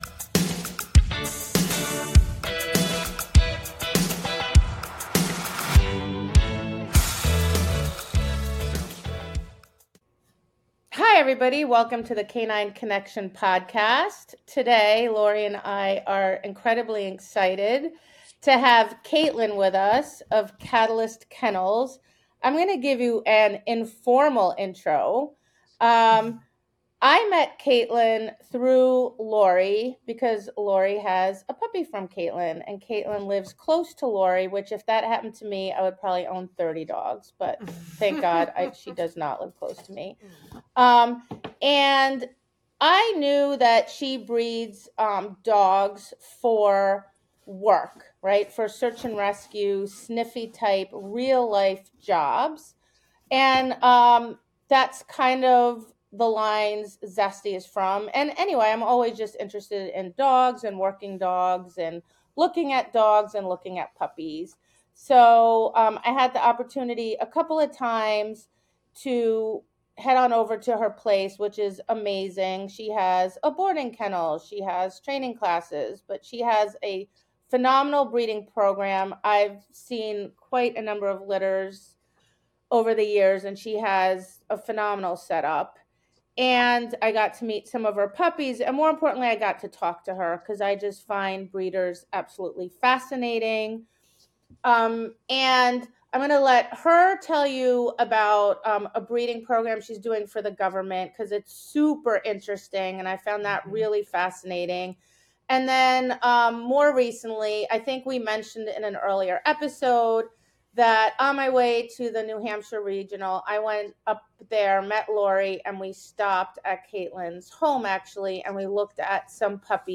Episode 22: Interview